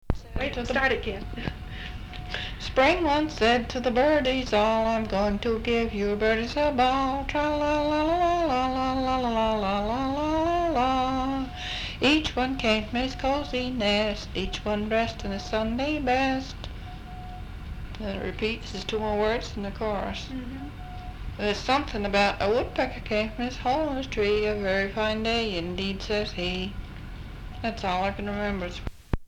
Folk songs, English--Vermont
sound tape reel (analog)
Readsboro, Vermont